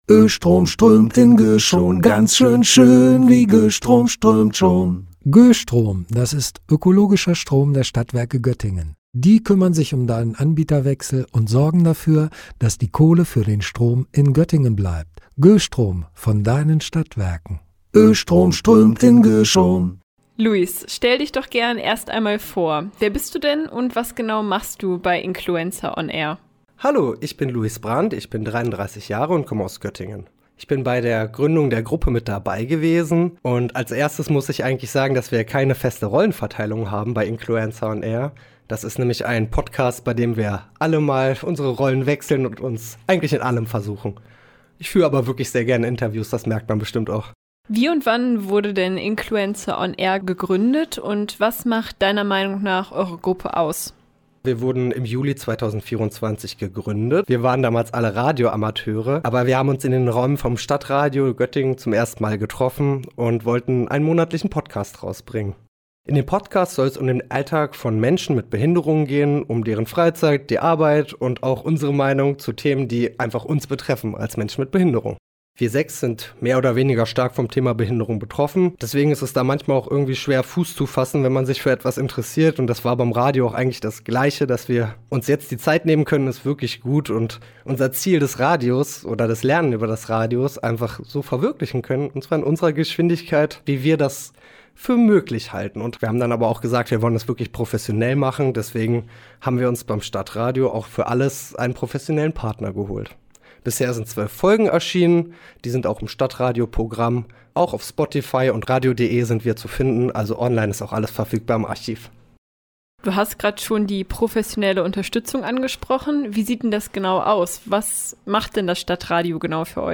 Inklu2_Kollegengesprch_Inkluencer-playout.mp3